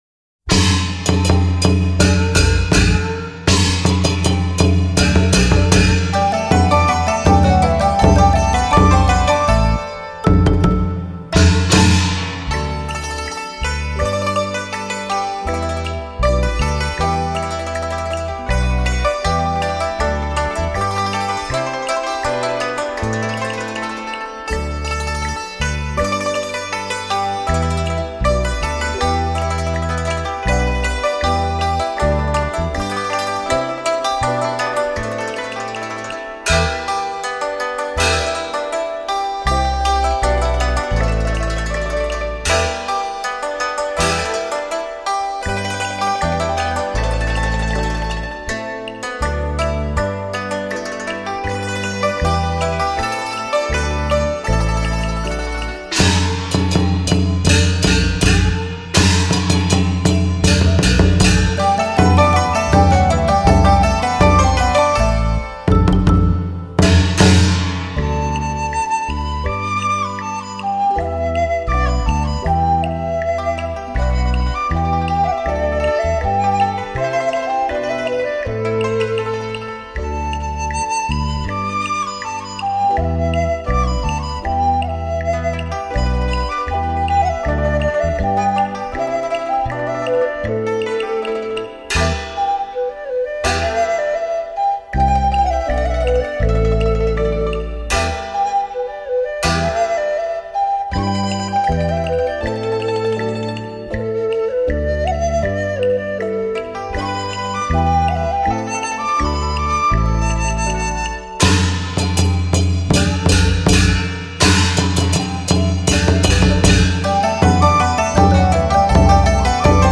这张cd所有乐器结像力之高难得一见。
每一件都有立体感，音色清爽悦耳，笛子音调高而又不[叫]，提琴音韵有如高山流水，敲声乐器真切动人。
乐音松容、舒畅、自然地奏出。
除了每件乐器音色极准之外，深、阔度都甚好，每件乐器人声定位都有出色表现。